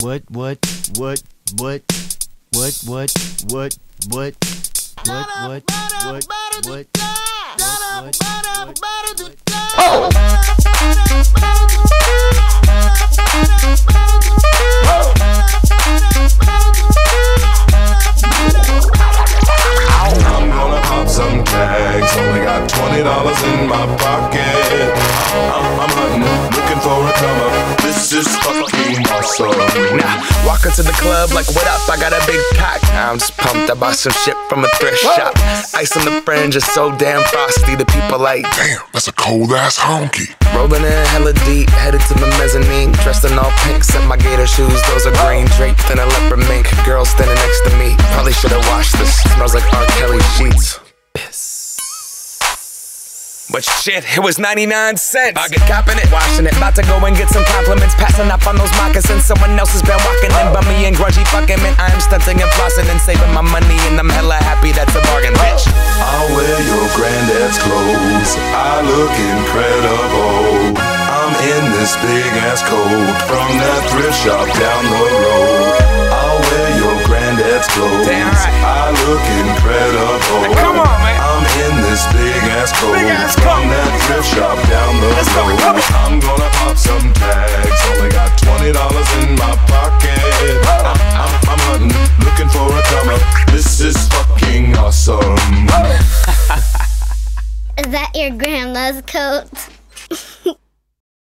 BPM95-95
Audio QualityMusic Cut